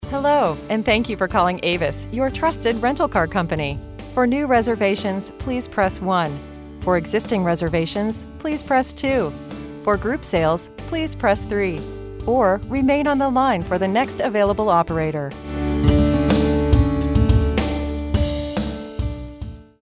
Telephony_Avis_friendly_informative
Neutral, Mid-Atlantic
Middle Aged